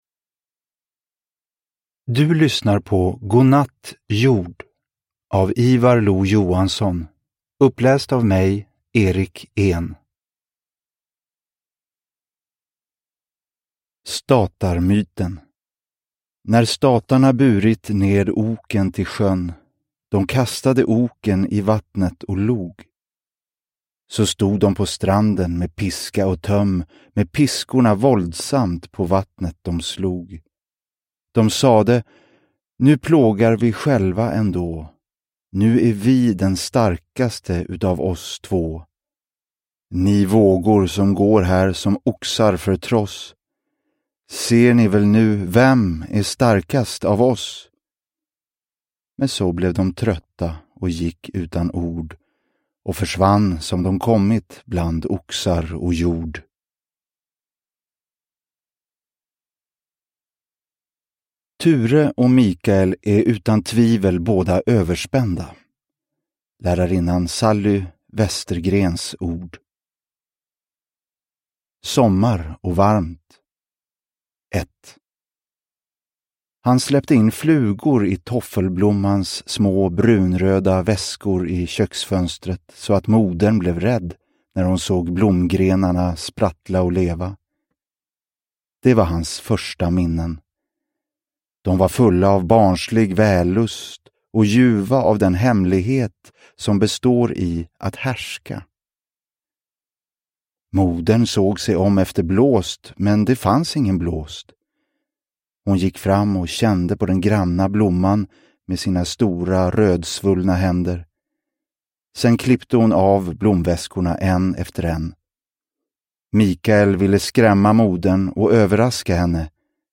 Godnatt, jord – Ljudbok – Laddas ner